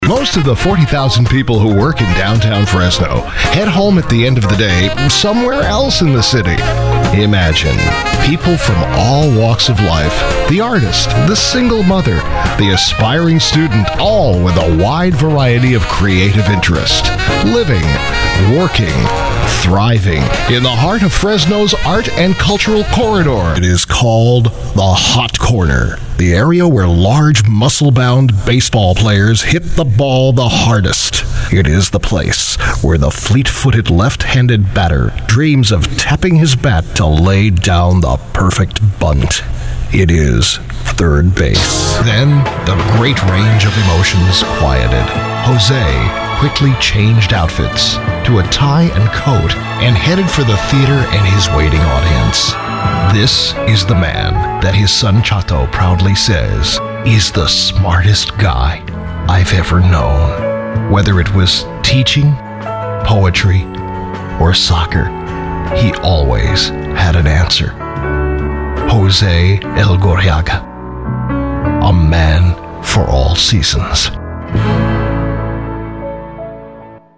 VOICEOVER DEMOS
Narration Demo
Broadcast-quality home studio with digital delivery; ISDN with 24-hour notice.